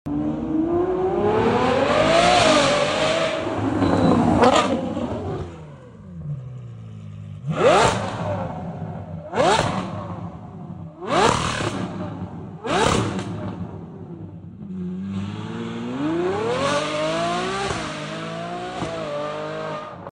Ferrari 812 SuperFast 💪😎 Sound Effects Free Download